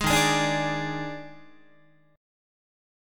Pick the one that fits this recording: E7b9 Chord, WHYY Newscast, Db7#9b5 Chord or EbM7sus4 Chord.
EbM7sus4 Chord